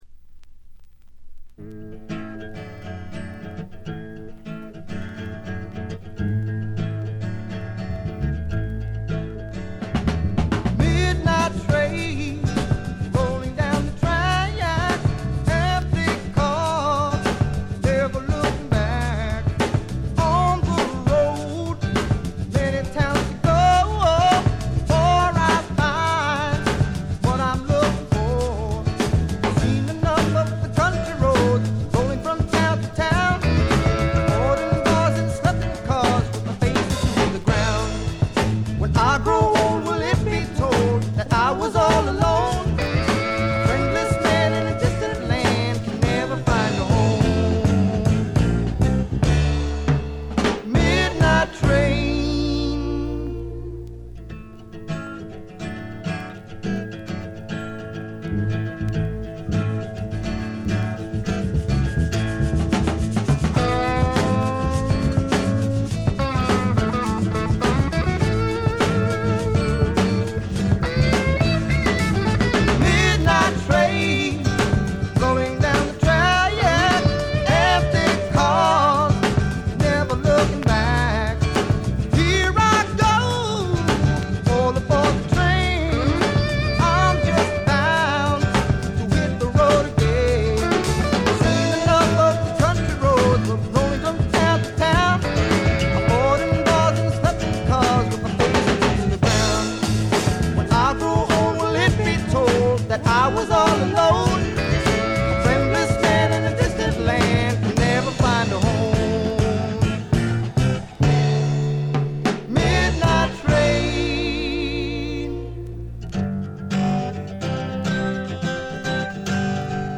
A1からA2の曲間とA2序盤、B2の静音部でチリプチが目立ちますが鑑賞を妨げるほどではないと思います。
で、内容はザ・バンドからの影響が色濃いスワンプ裏名盤であります。
試聴曲は現品からの取り込み音源です。